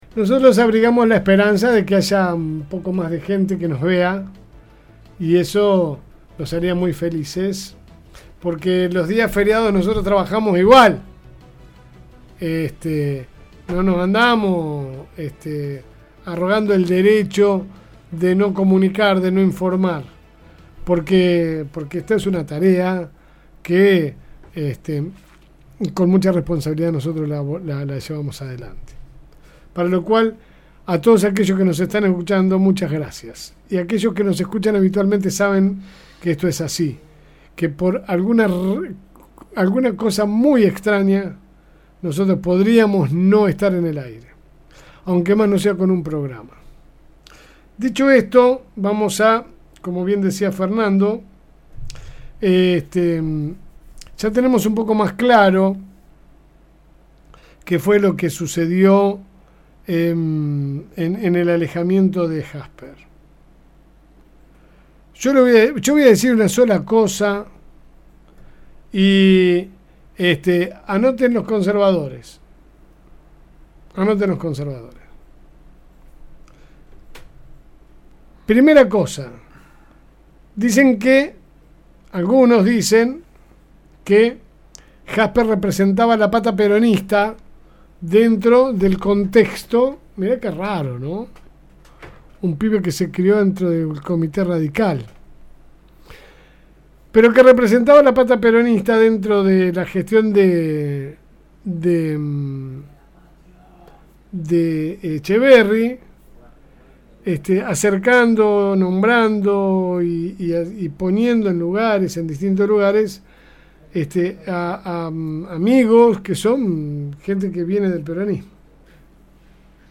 Editorial LSM